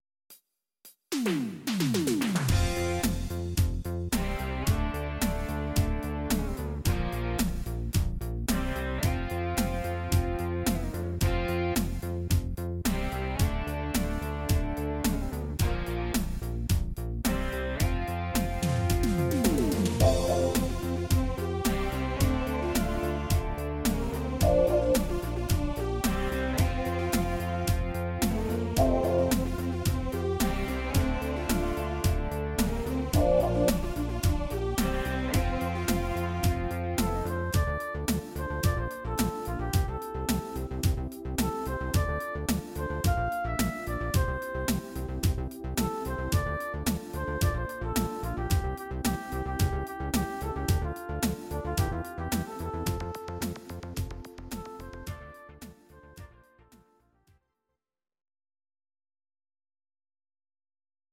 Audio Recordings based on Midi-files
Pop, Disco, 1980s